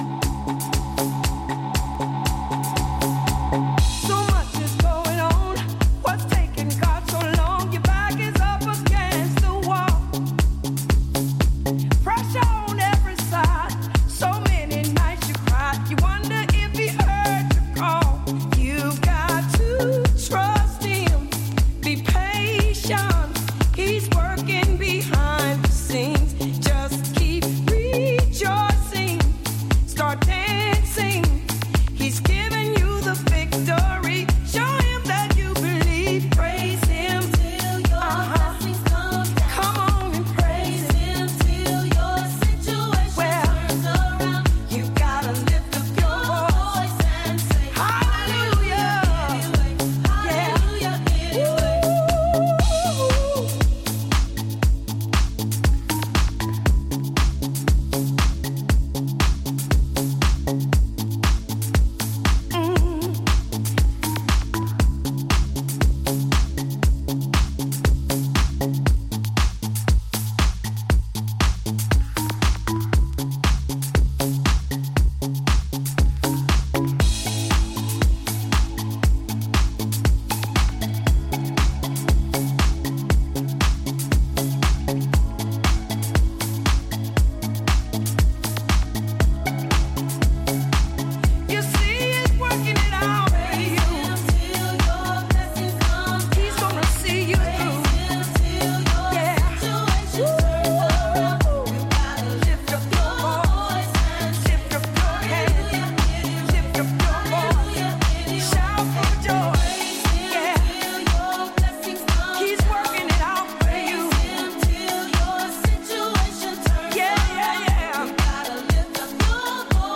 今後、定番的に楽しめるだろうオーセンティックなソウル感溢れる歌物ハウス集です！
ジャンル(スタイル) HOUSE / SOULFUL HOUSE